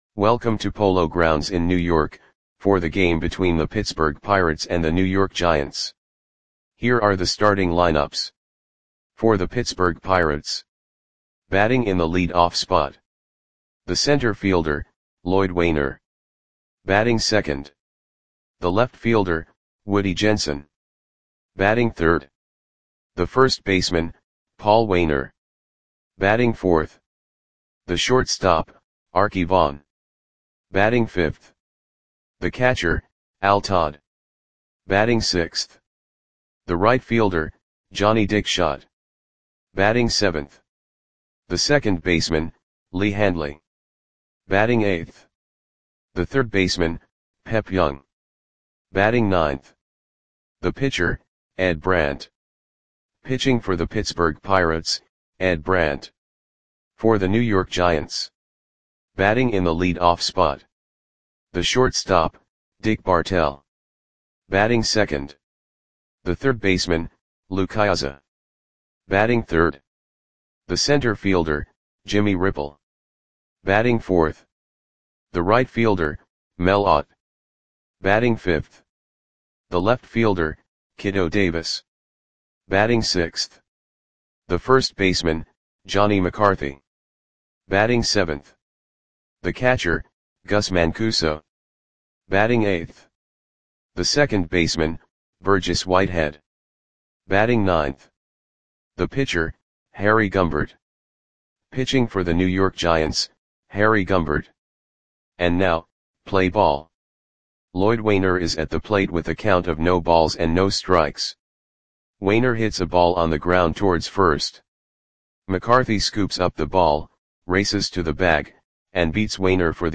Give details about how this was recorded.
Pirates 2 @ Giants 5 Polo GroundsJune 7, 1937 (No Comments)